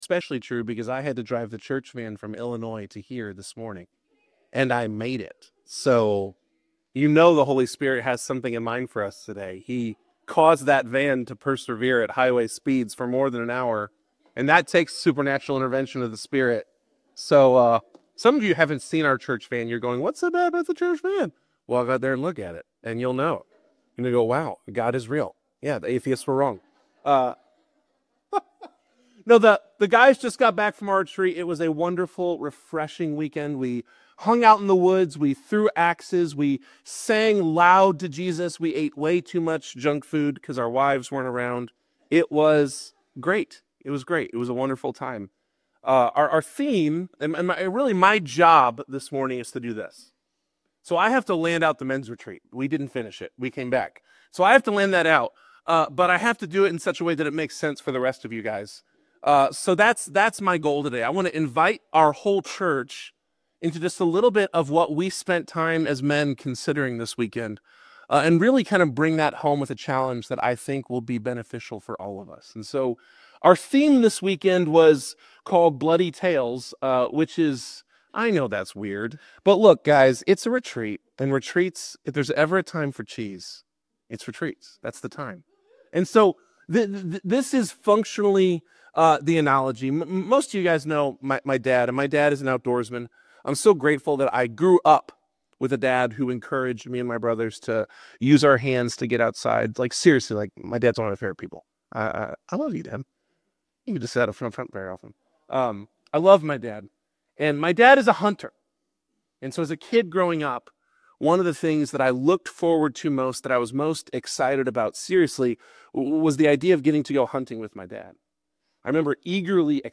Hound of Heaven - Stand Alone Sermon (1 John 4:7-12)